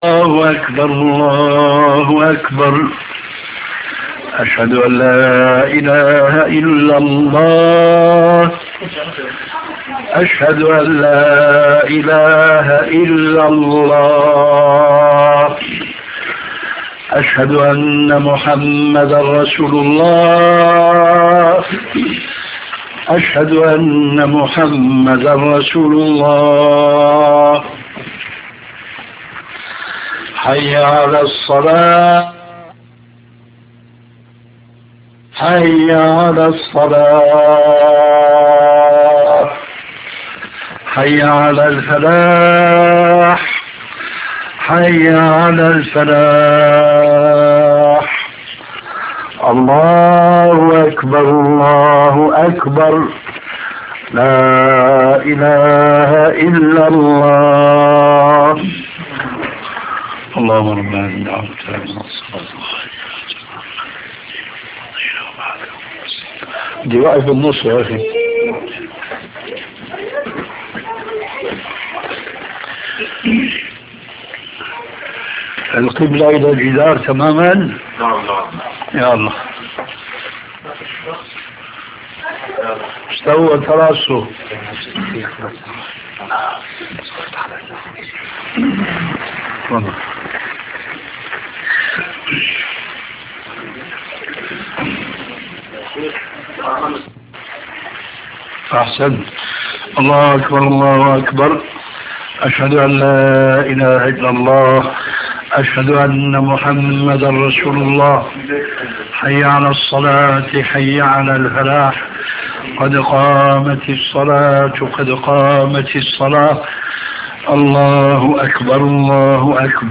Cheikh al Albany fait l'Adhan, l'Iqamat et récite sourate al fatiha
albani adhan iqama fatiha.rm